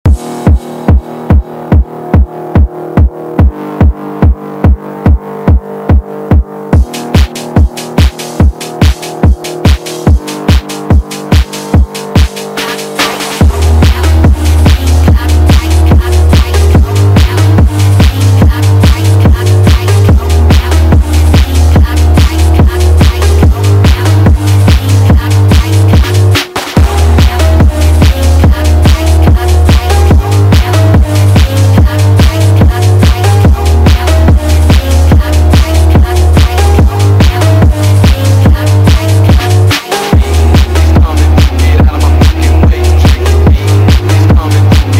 Anima Wow (Kawai Meme Sound)